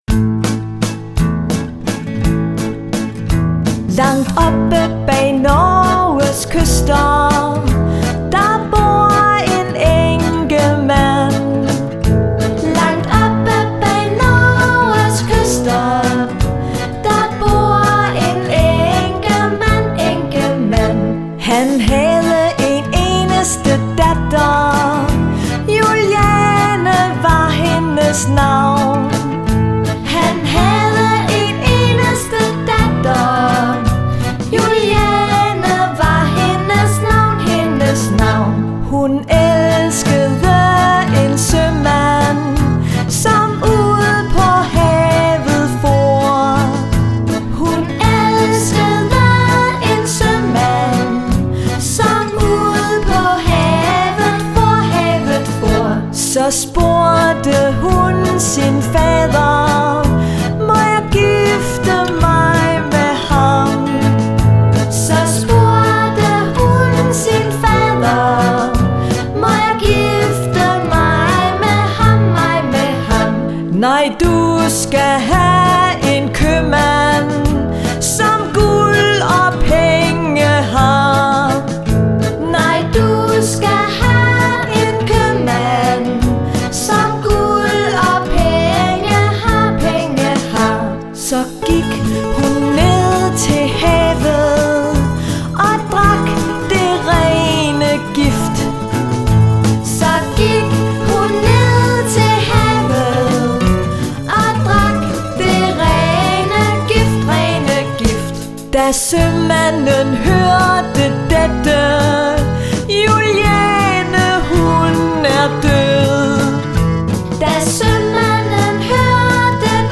sang kun lyd